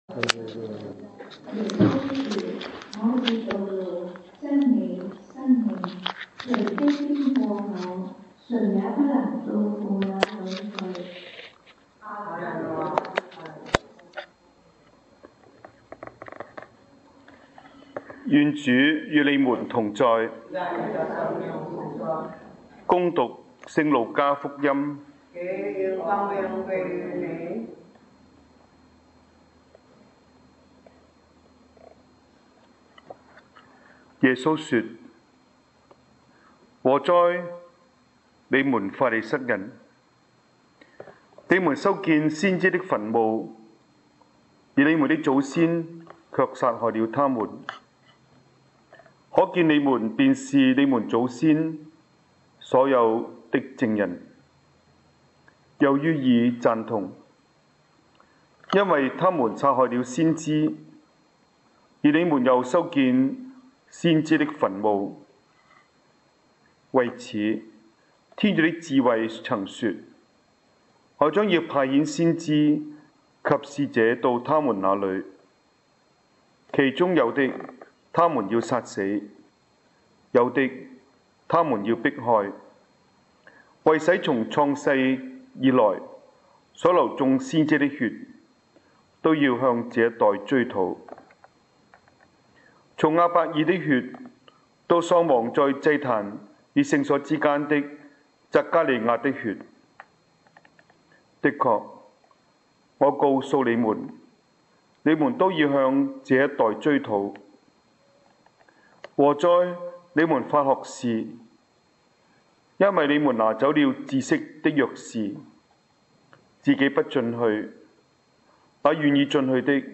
在Honolulu Carmelite_Convent讲道